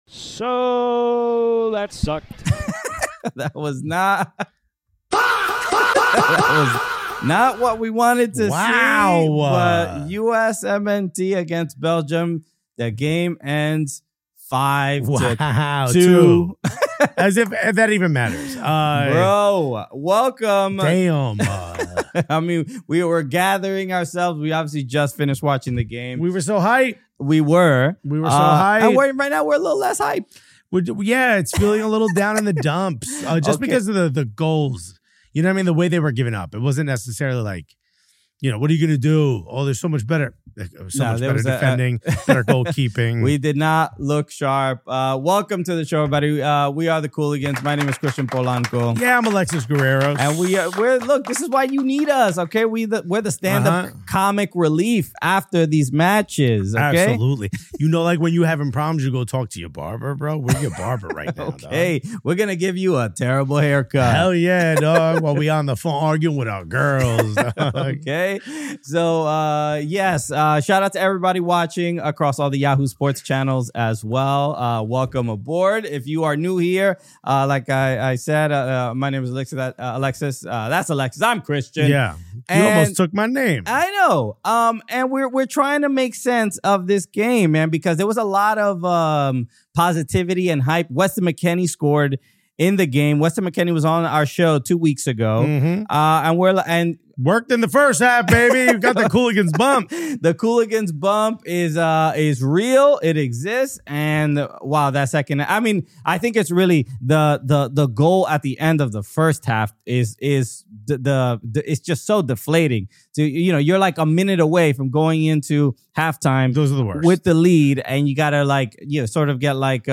The USMNT got hit hard in a 5-2 loss to Belgium, and we went live immediately after the final whistle to make sense of it all.
We break down Mauricio Pochettino’s tactics, decisions, and whether he got it completely wrong on the night. Then, USMNT legend Marcelo Balboa joins us to offer real insight into what failed on the pitch and what the team must fix before it’s too late.